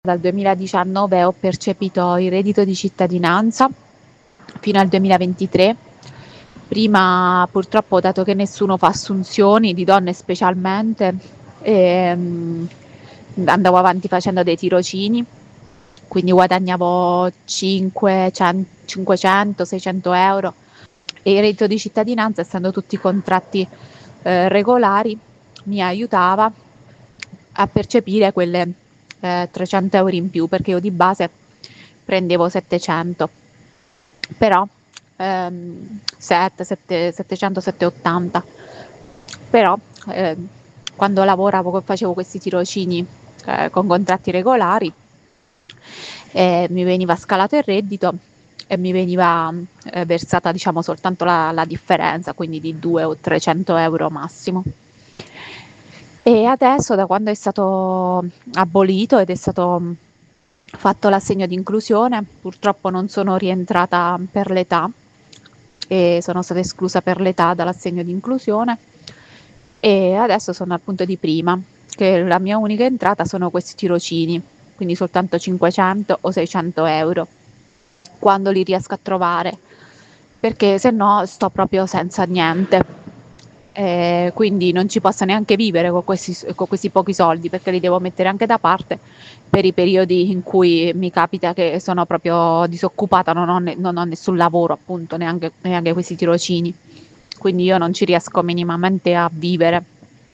Tra queste persone c’è anche una giovane ragazza di Pescara, che ci ha raccontato la sua storia.